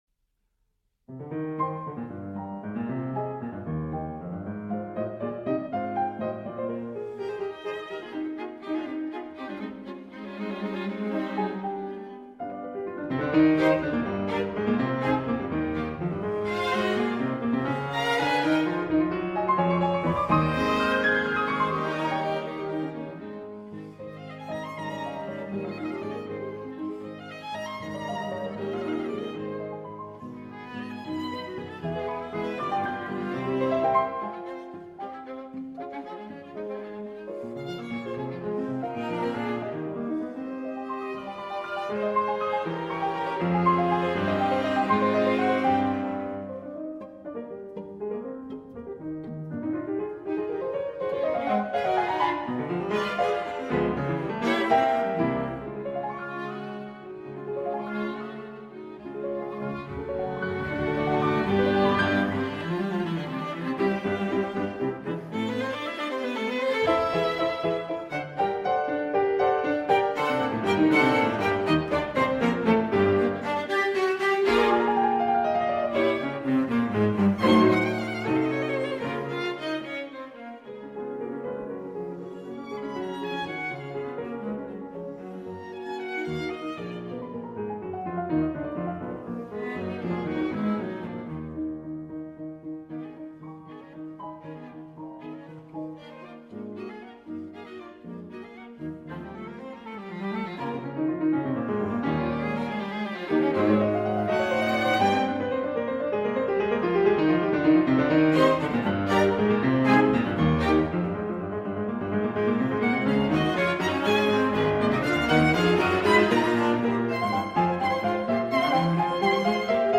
Soundbite 2nd Movt